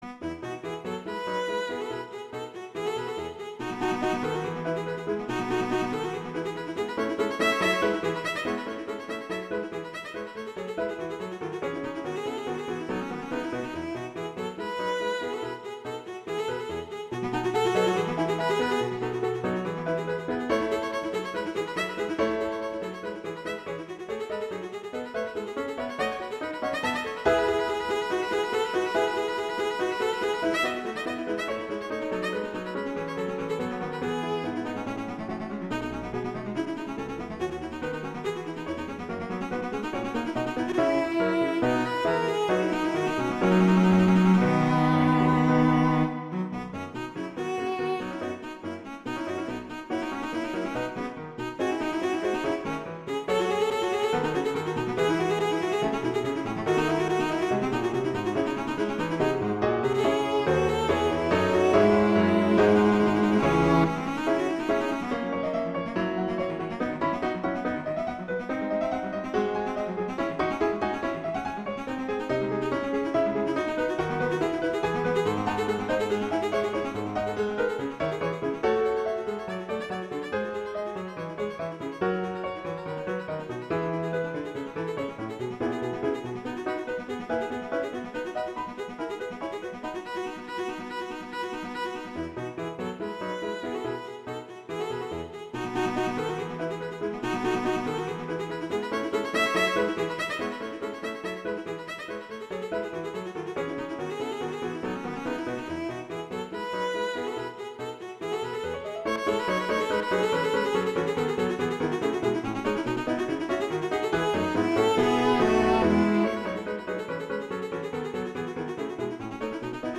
cello and piano
classical
Allegro vivo